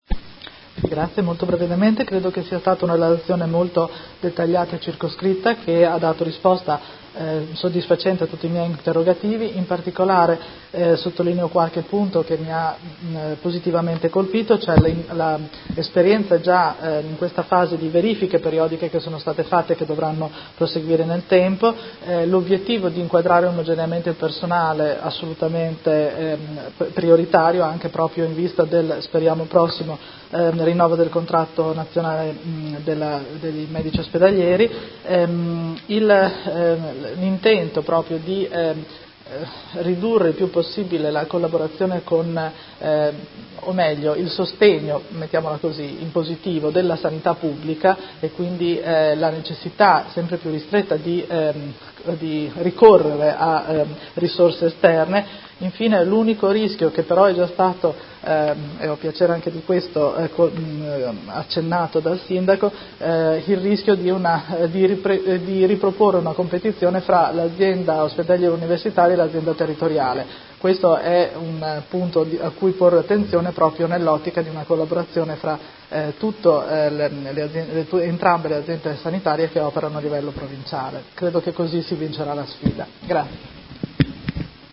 Seduta del 21/03/2019 Replica a risposta Sindaco. Interrogazione della Consigliera Pacchioni (PD) avente per oggetto: Sulla fusione Policlinico-Baggiovara